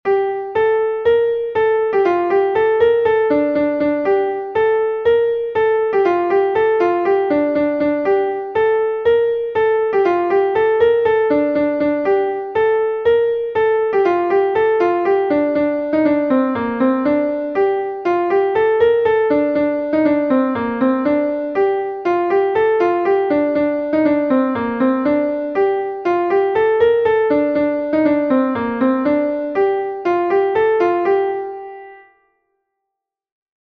a Laridé from Brittany